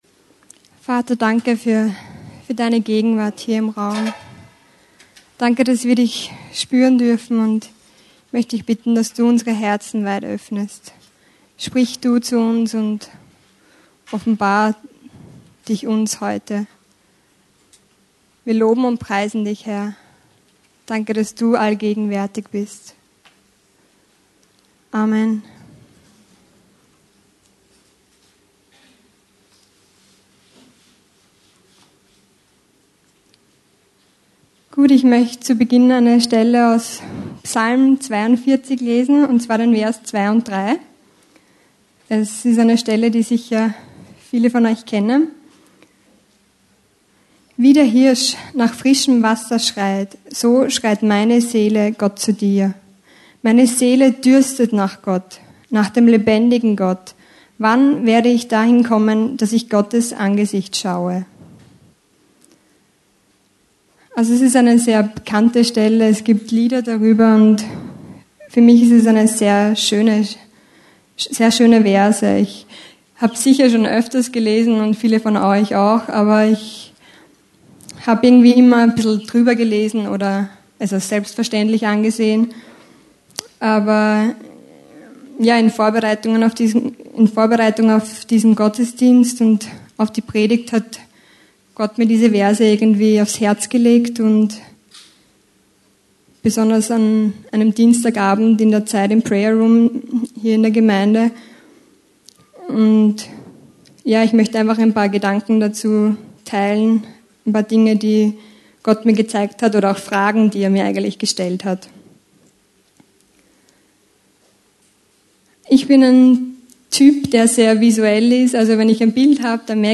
Hier finden Sie das Predigt Archiv für das Jahr 2013.